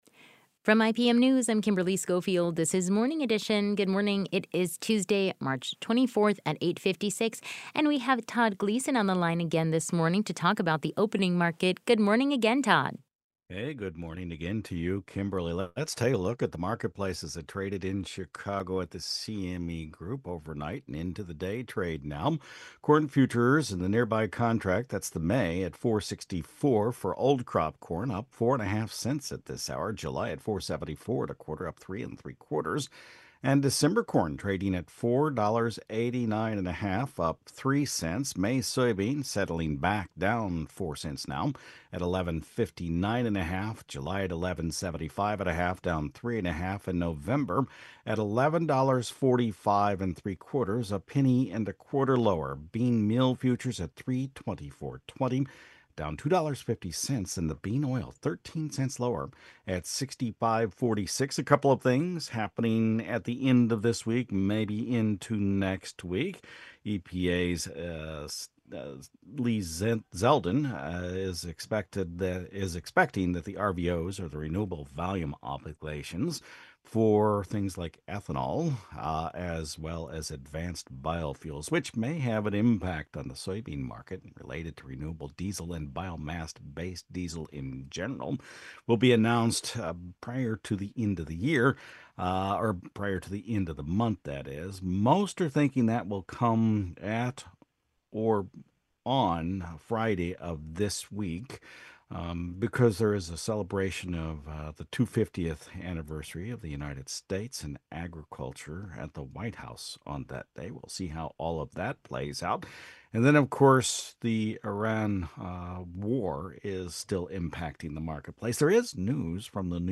Opening Market Report